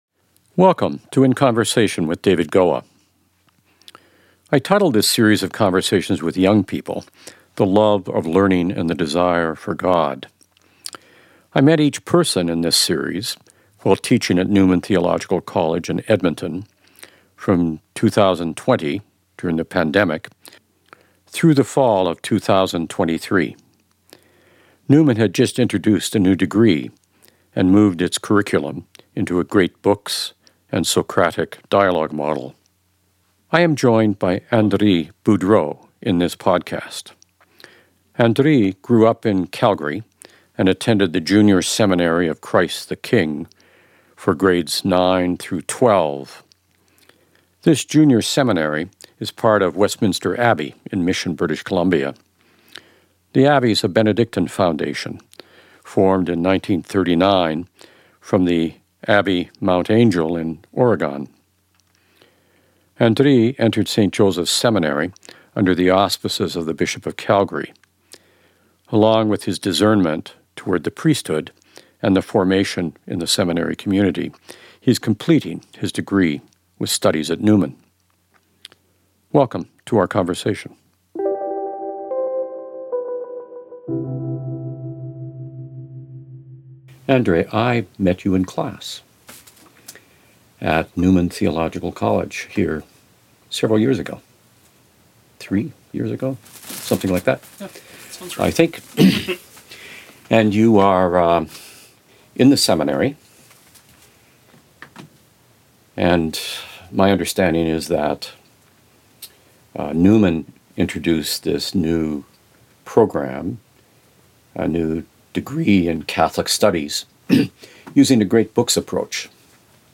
I title this series of conversations with young people The Love of Learning and the Desire for God.